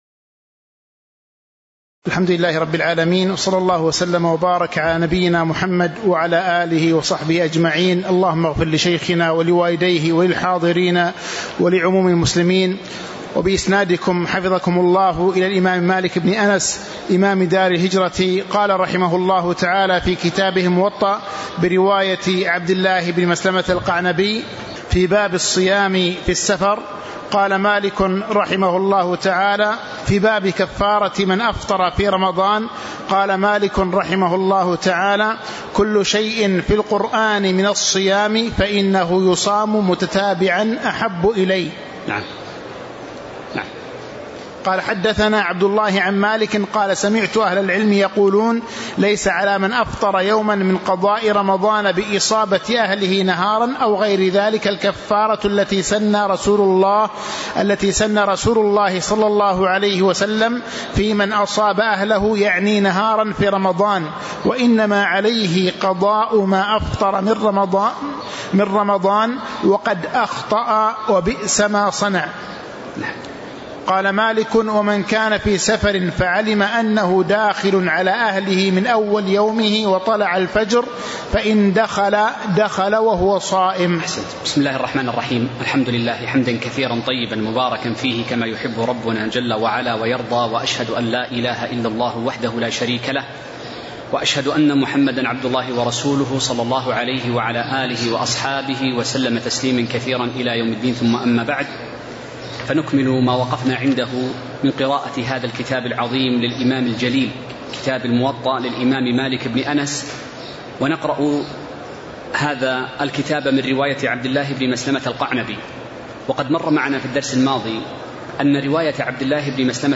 تاريخ النشر ٢٤ شعبان ١٤٤٦ هـ المكان: المسجد النبوي الشيخ